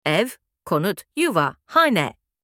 خانه به ترکی استانبولی؛ جامع‌ترین راهنما برای منزل شما + تلفظ
house-in-turkish.mp3